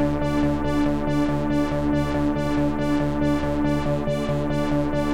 Index of /musicradar/dystopian-drone-samples/Tempo Loops/140bpm
DD_TempoDroneD_140-D.wav